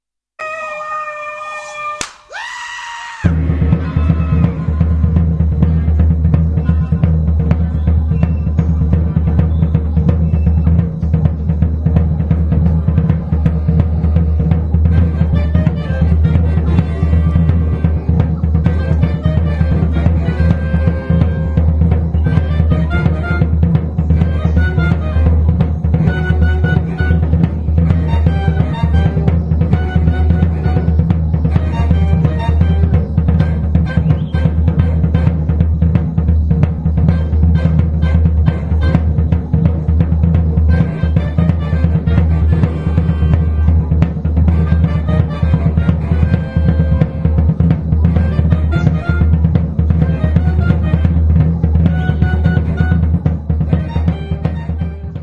arrangée pour trio
clarinette, clarinette basse
saxophones alto et baryton